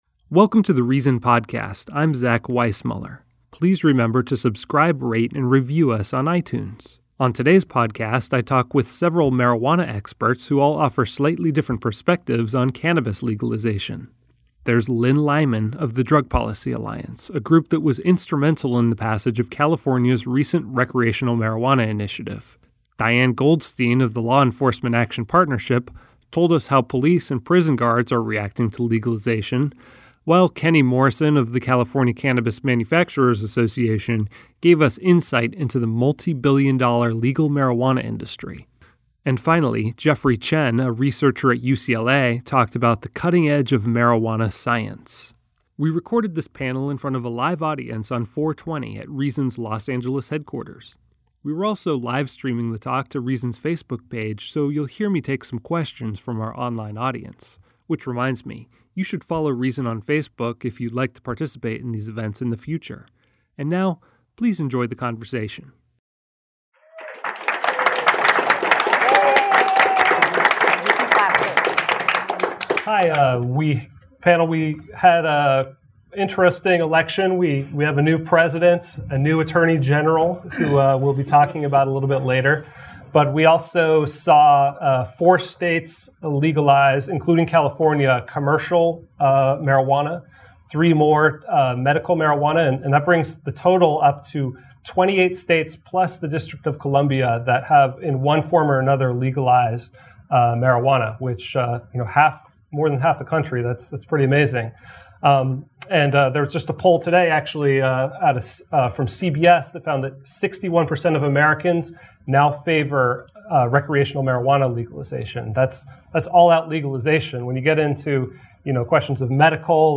Reason sat down with experts and advocates to discuss the state legalization, science, and the marijuana industry.